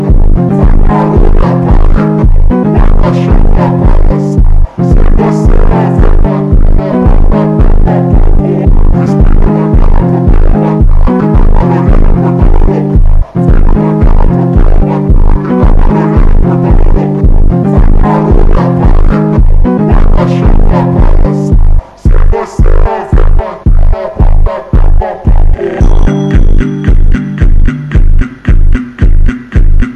Жанр: Пост-хардкор / Хард-рок